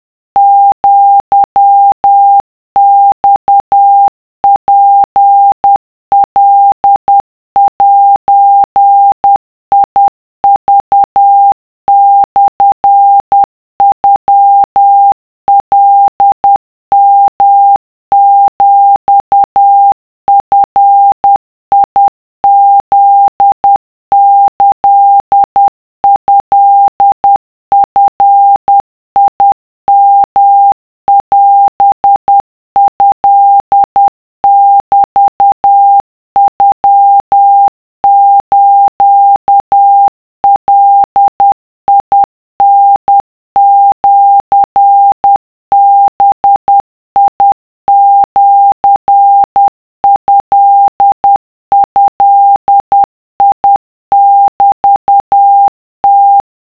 【cw】2【wav】 / 〓古文で和文系〓
とりあえず50CPM (=10WPM)で作ってみた